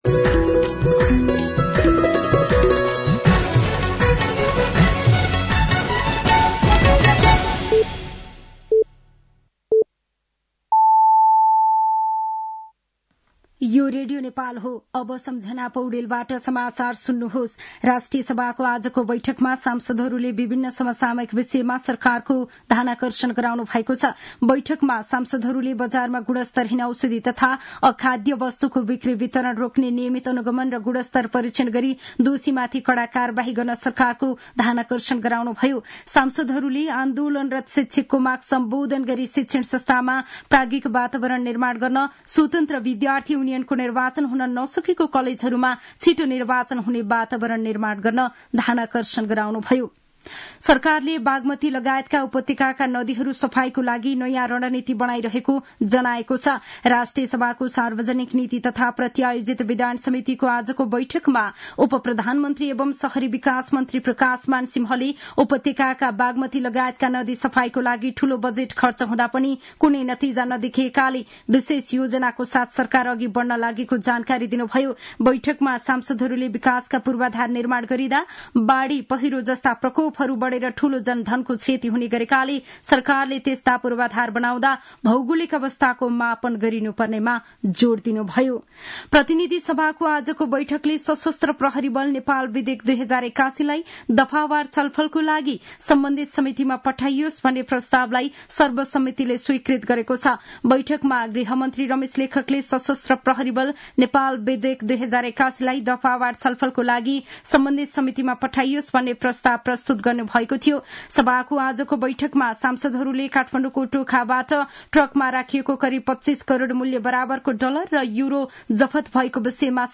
साँझ ५ बजेको नेपाली समाचार : ६ चैत , २०८१